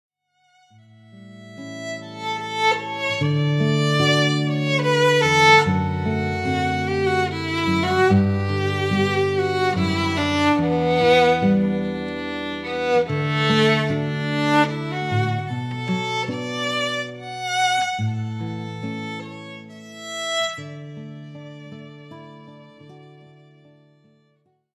violinist